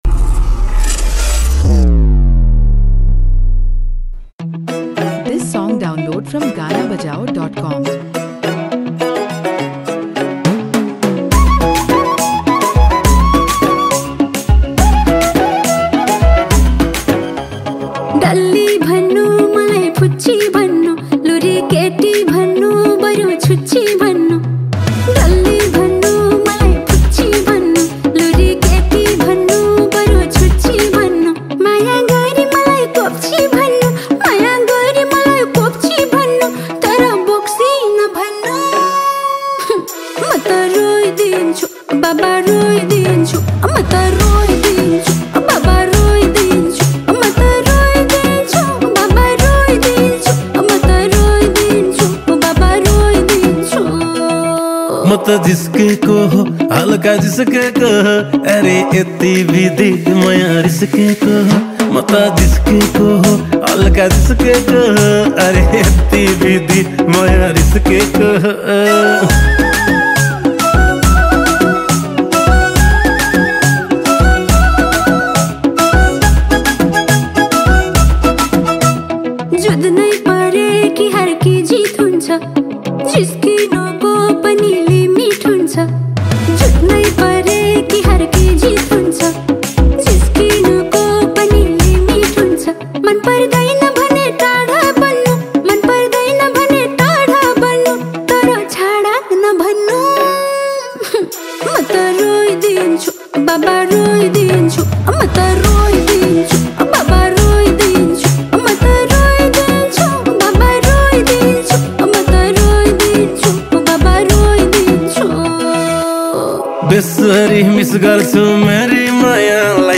New Lok Dohori Song